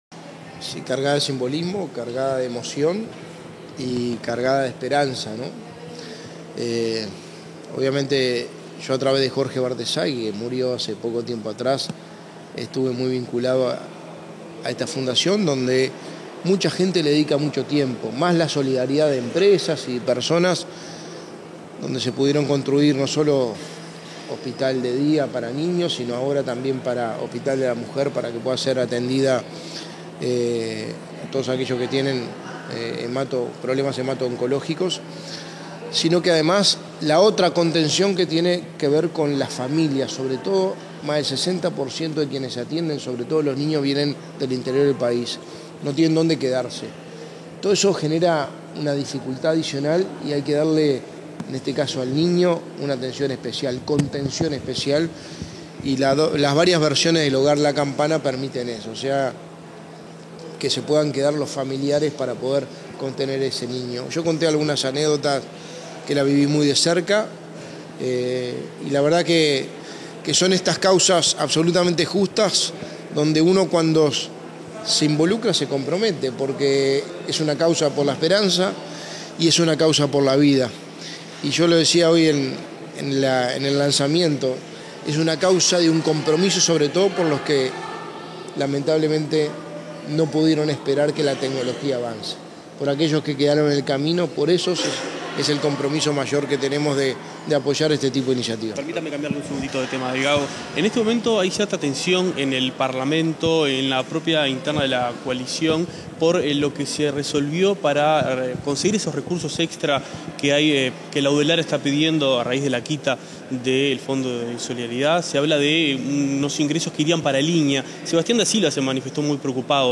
Declaraciones a la prensa del secretario de la Presidencia
Declaraciones a la prensa del secretario de la Presidencia 17/08/2022 Compartir Facebook X Copiar enlace WhatsApp LinkedIn Tras el lanzamiento de Relevo por la Vida de la Fundación Peluffo Giguens, este 17 de agosto, el secretario de la Presidencia de la República, Álvaro Delgado, realizó declaraciones a la prensa.